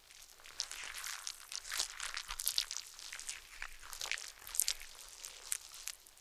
BloodPostEffect.wav